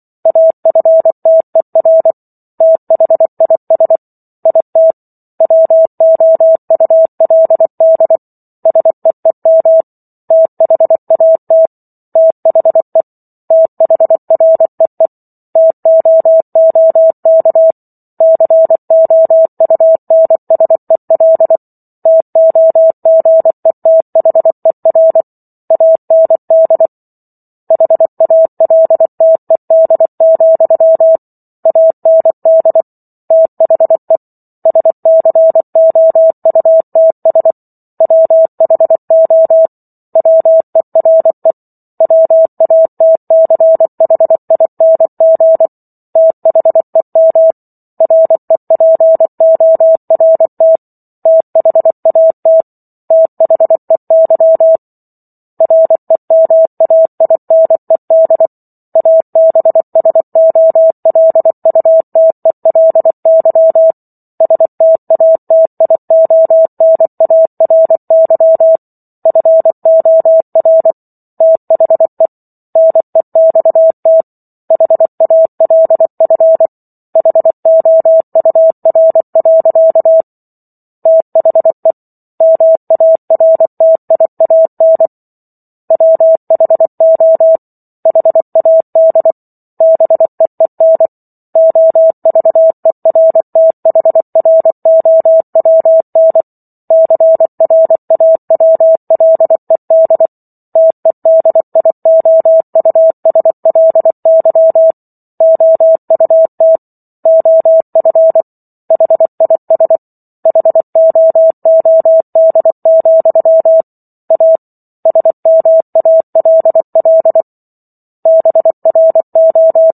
War of the Worlds - 15-Chapter 15 - 24 WPM